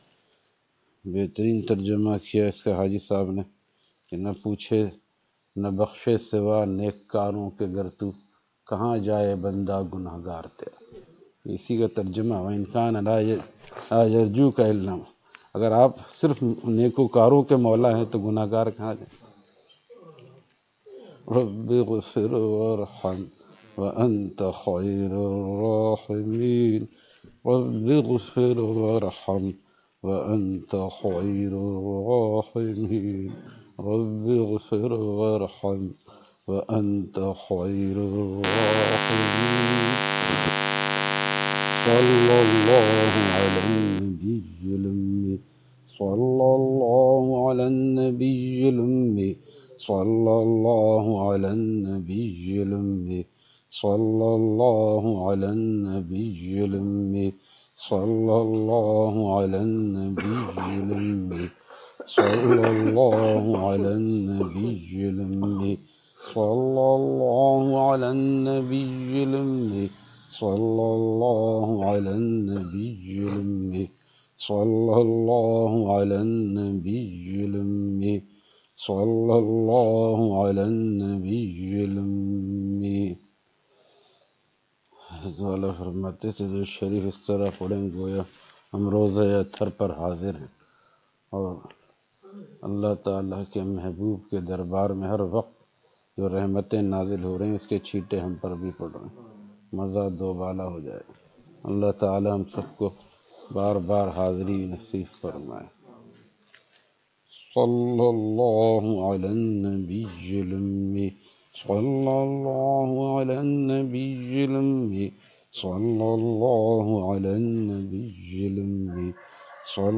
Zikar Majlis at Kohsar Society, Hyderabad